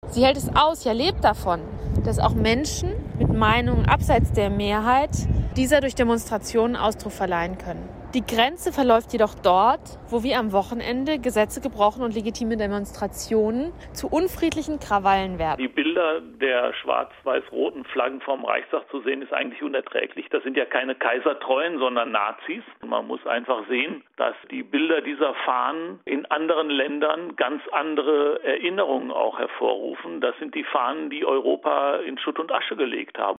Am Rande der großen Anti-Corona-Demo hatten Demonstranten die Treppe des Reichstages gestürmt und waren dort erst von Polizisten gestoppt worden. Katrin Helling-Plahr von der FPD und Sozialdemokrat Rene Röspel sagen: trotz allem ist unsere Demokratie stark und wehrhaft.